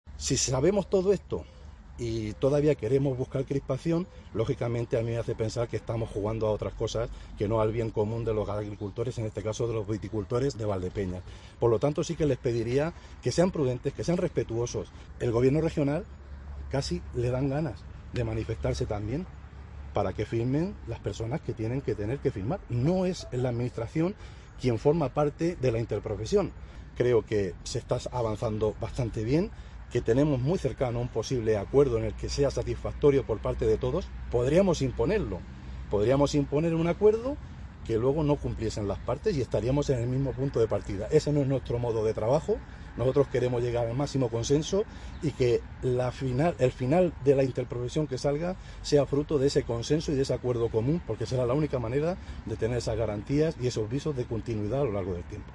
• Durante el primer foro Conversa 'Ganadería: Investigación y futuro en Castilla-La Mancha', organizado por Cadena Ser, que ha tenido lugar en Centro de Investigación Ganadera (CERSYRA) de Valdepeñas.
corteconsejero.mp3